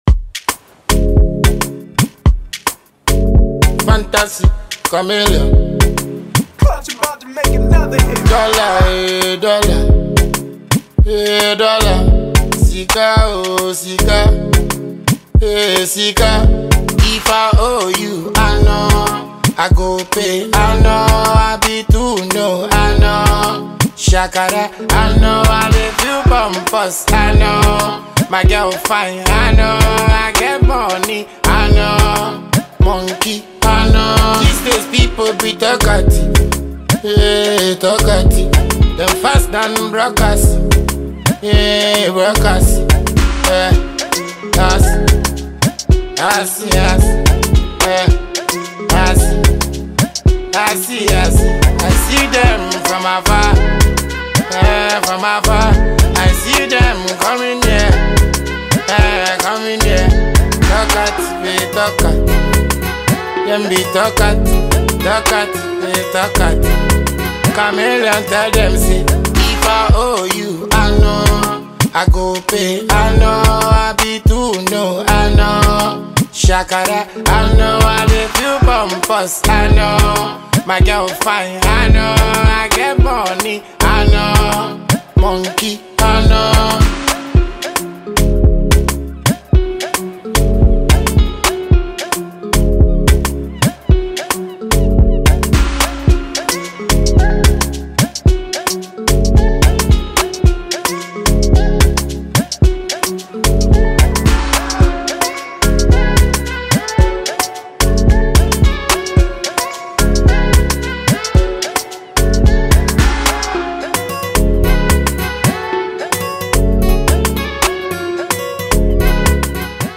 a veteran and talented Ghanaian dancehall musician